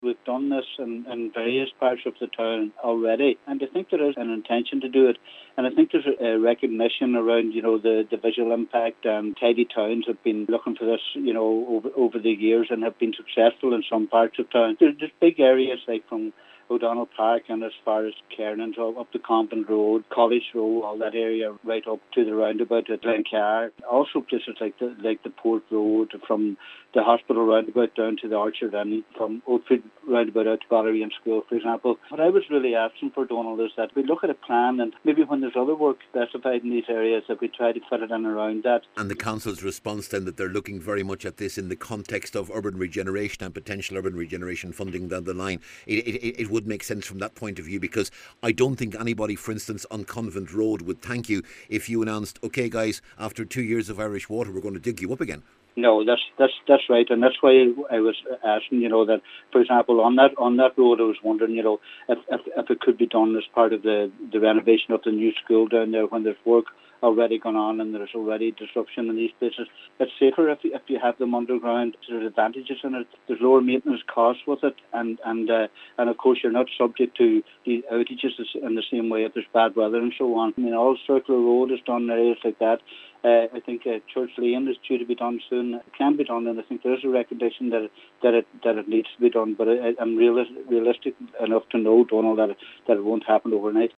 Cllr Kavanagh accepts this will be a long term project: